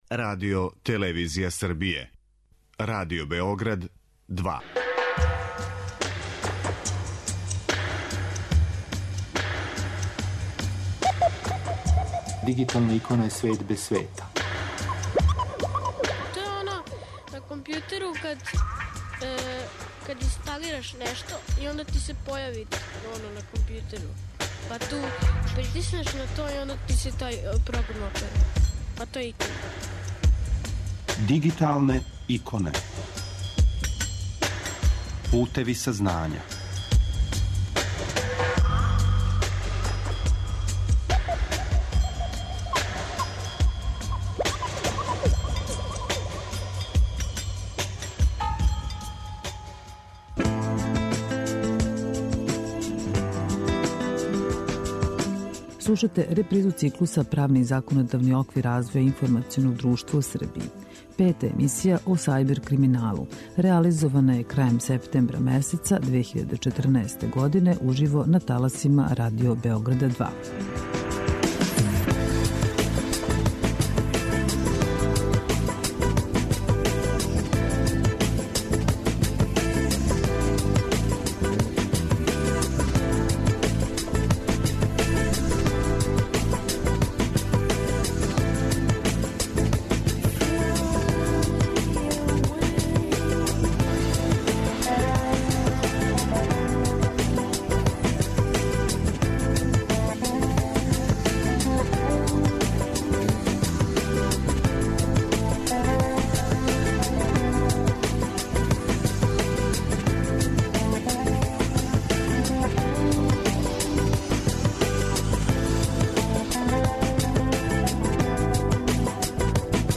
Слушате репризу циклуса 'Правни и законодавни оквир развоја информационог друштва у Србији'. Пета емисија, о сајбер криминалу, реализована је крајем септембра месеца 2014, уживо на таласима Радио Београда 2.